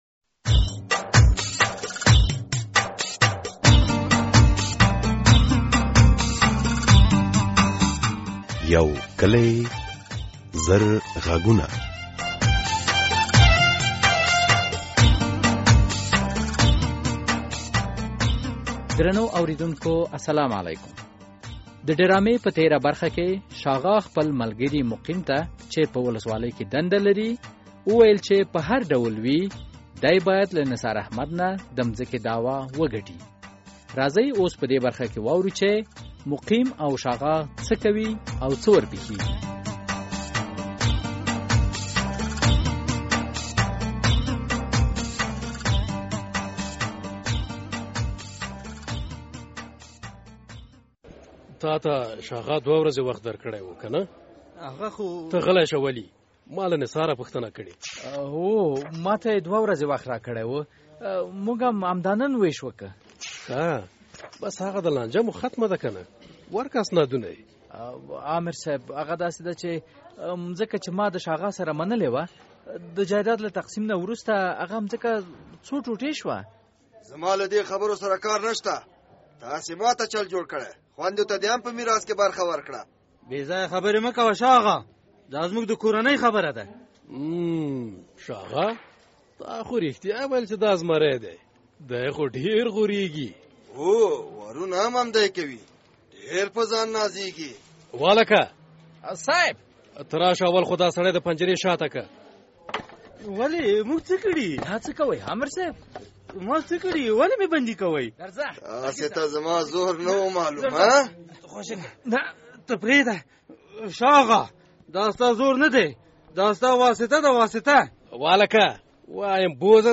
د یو کلي زرغږونو ډرامې ۹۸ برخه